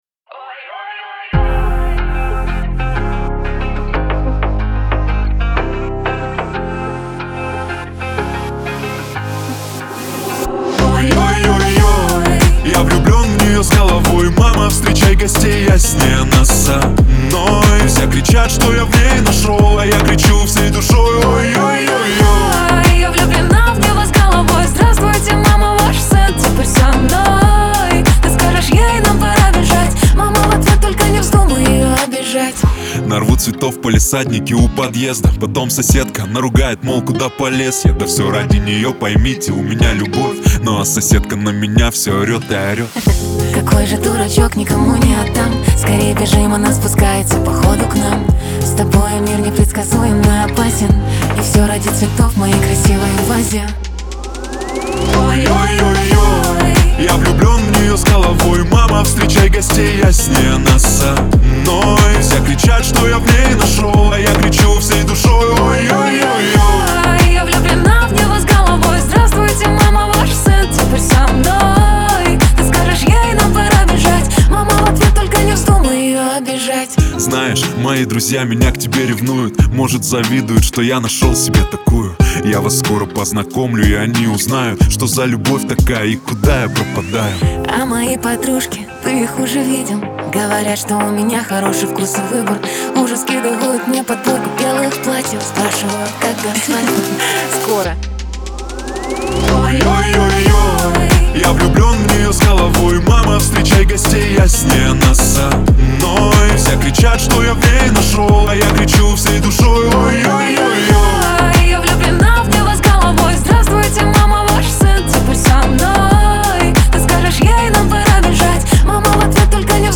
эстрада
pop , дуэт